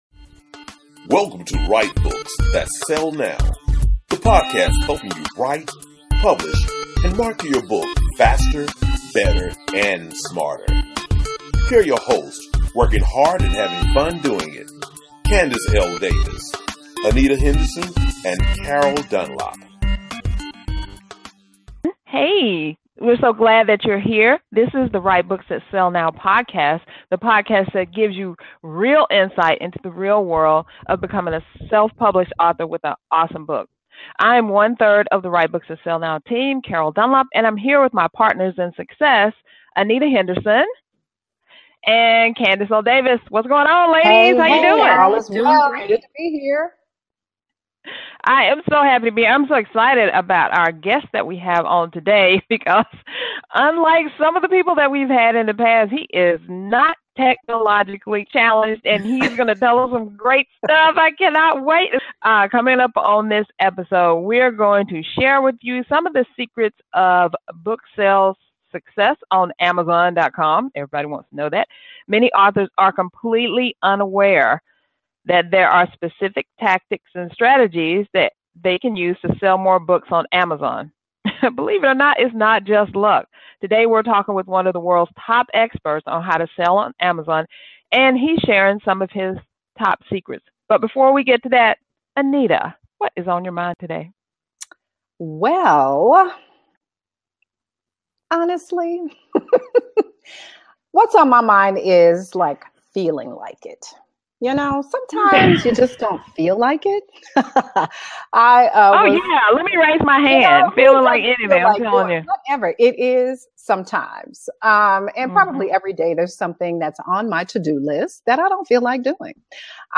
Guests include publishing and marketing professionals, business insiders, lifestyle strategists, and other subject matter experts who share insights on maximizing life and business.